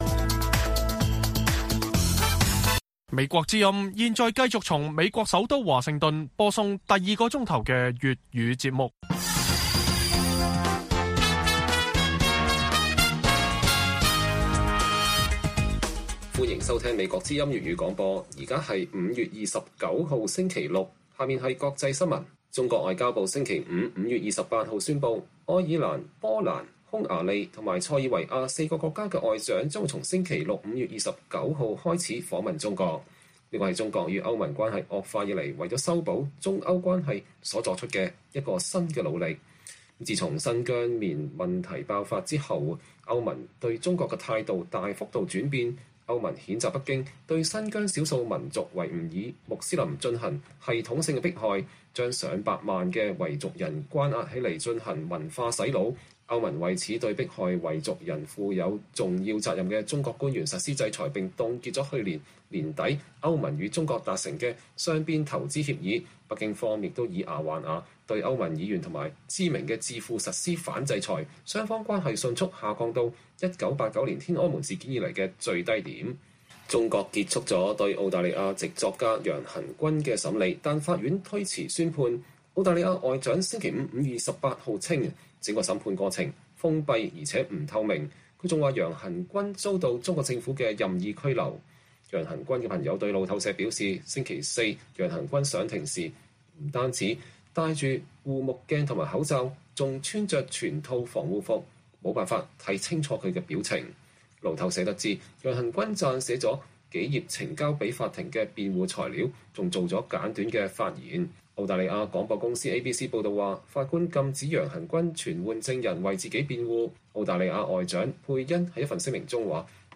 粵語新聞 晚上10-11點 布林肯譴責中國制裁美宗教領袖 摩爾：中共所為終將失敗